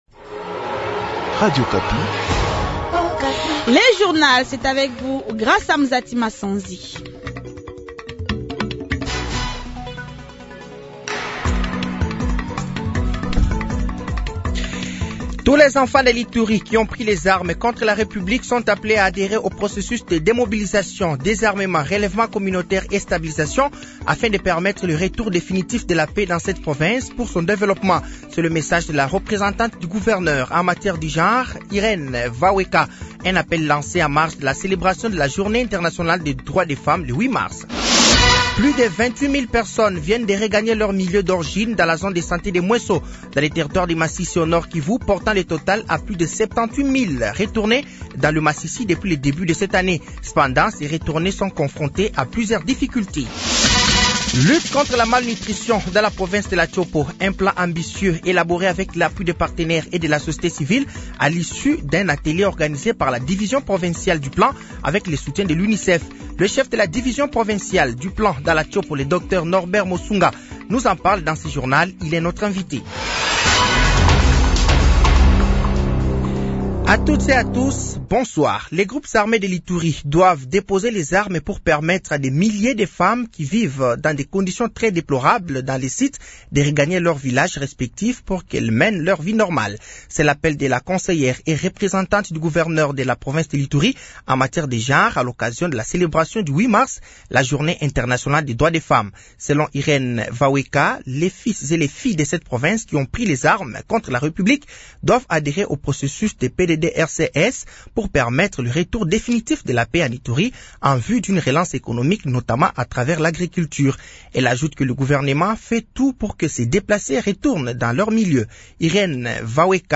Journal français de 18h de ce dimanche 09 mars 2025